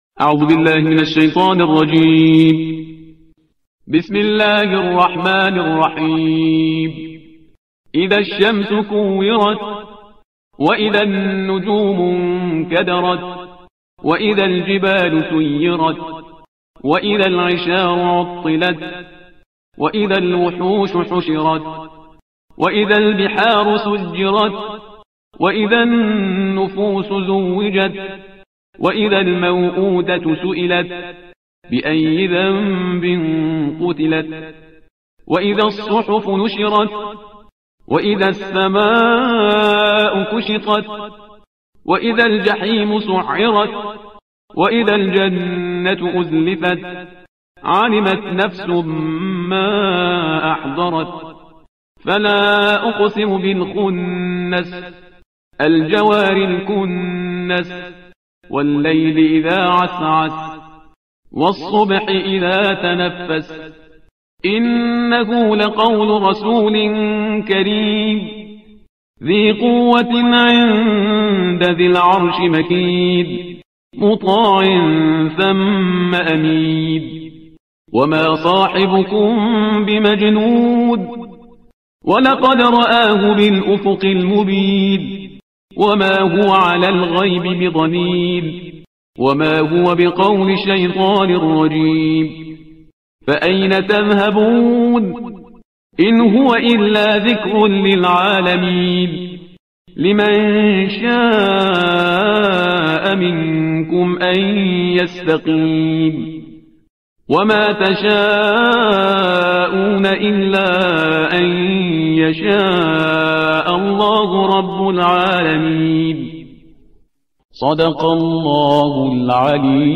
ترتیل صفحه 586 قرآن